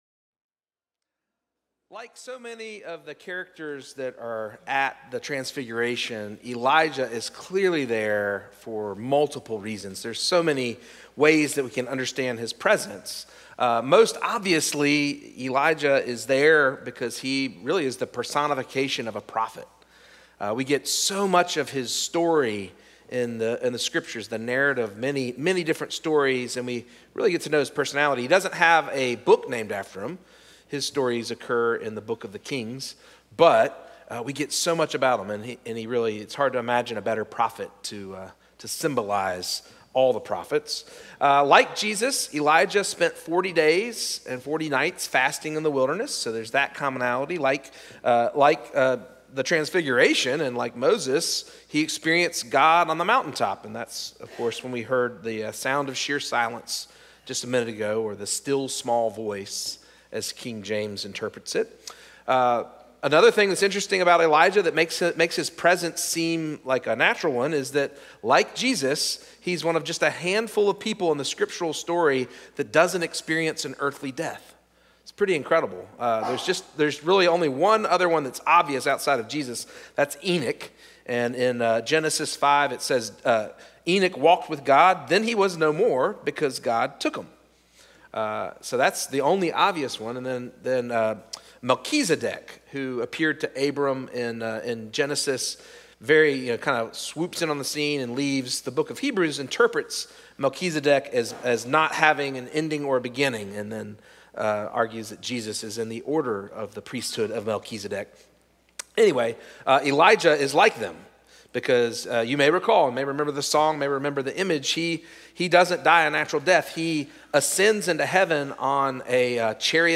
First Cary UMC's First Sanctuary Sermon "Here for God's Presence"